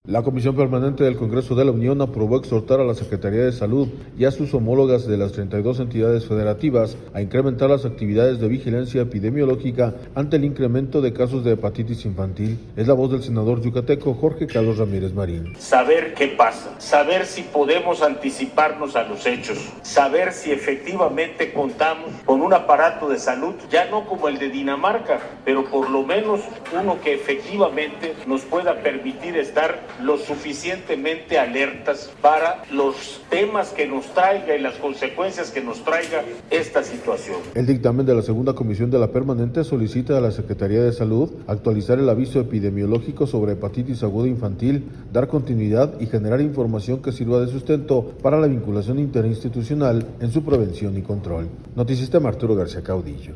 La Comisión Permanente del Congreso de la Unión, aprobó exhortar a la Secretaría de Salud y a sus homólogas de las 32 entidades federativas a incrementar las actividades de vigilancia epidemiológica ante el incremento de casos de hepatitis infantil. Es la voz del senador yucateco, Jorge Carlos Ramírez Marín.